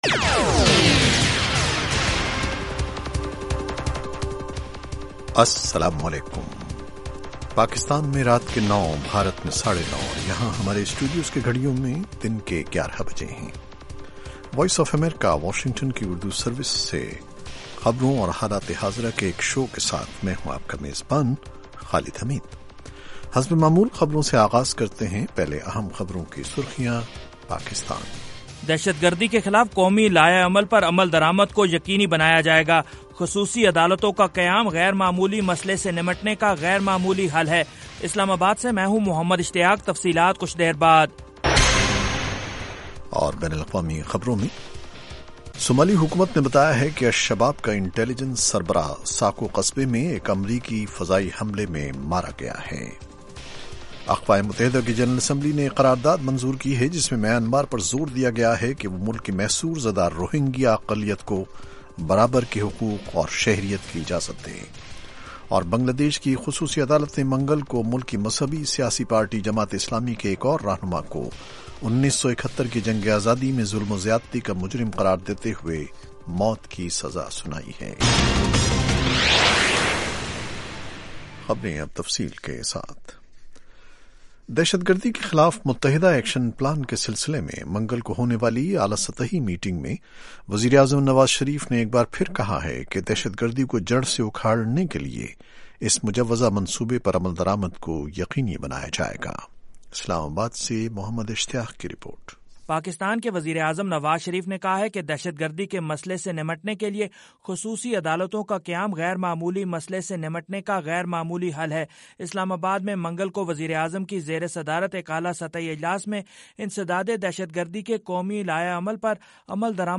ایک گھنٹے کے اس پروگرام میں سنیے خالد حمید کے ساتھ دن بھر کی اہم خبریں اور پاکستان اور بھارت سے ہمارے نمائندوں کی رپورٹیں۔ اس کے علاوہ انٹرویو، صحت، ادب و فن، کھیل، سائنس اور ٹیکنالوجی اور دوسرے موضوعات کا احاطہ۔